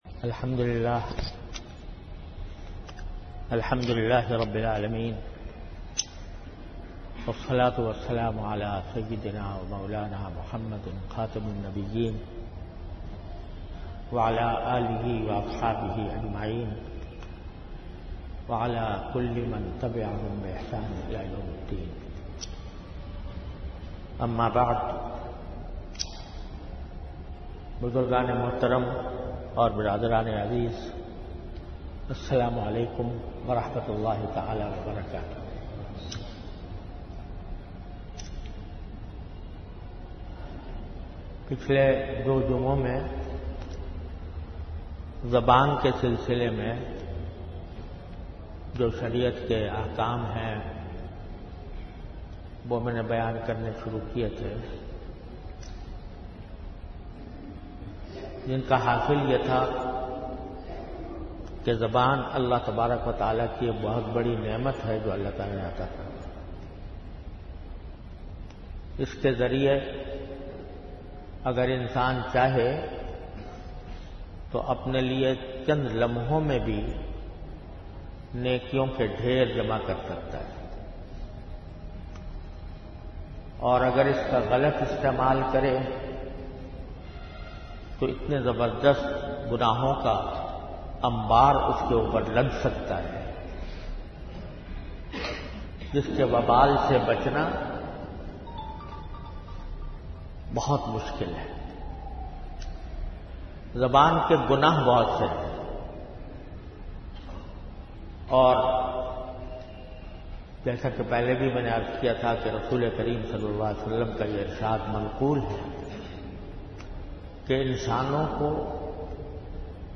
An Islamic audio bayan by Hazrat Mufti Muhammad Taqi Usmani Sahab (Db) on Bayanat. Delivered at Jamia Masjid Bait-ul-Mukkaram, Karachi.